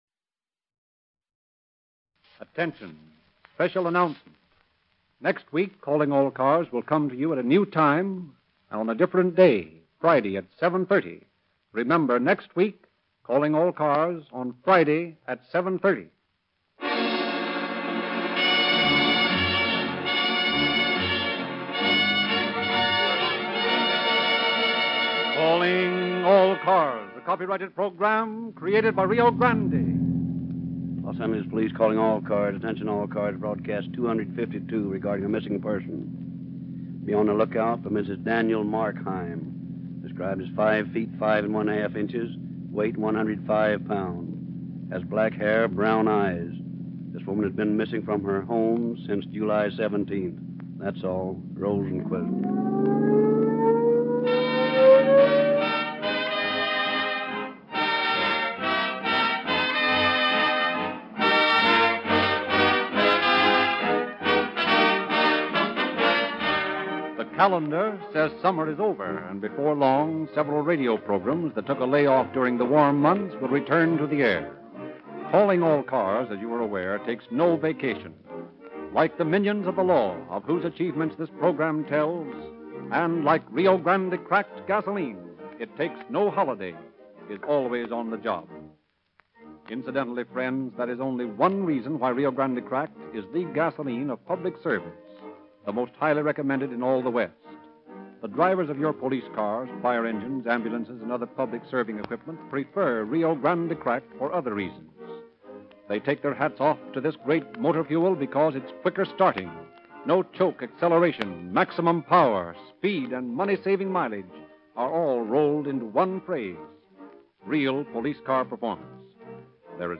Calling All Cars Radio Program